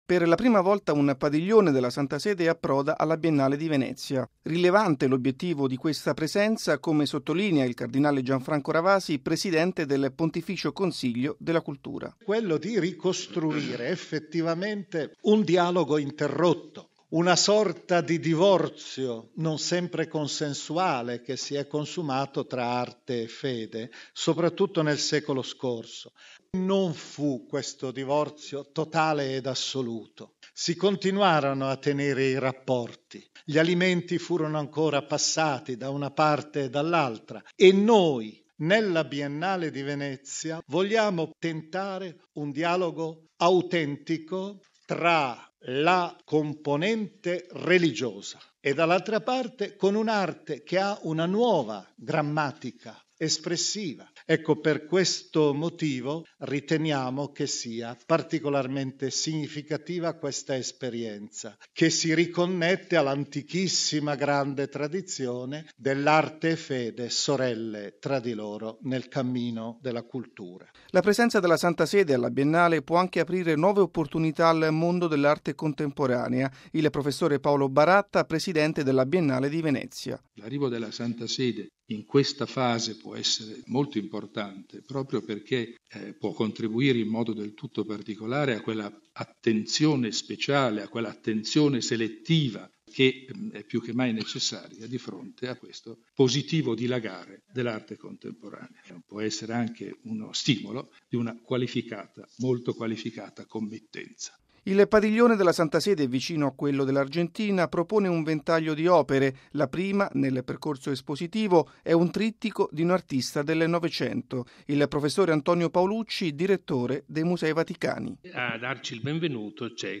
Lo spazio espositivo è stato presentato questa mattina nella Sala Stampa della Santa Sede. Il servizio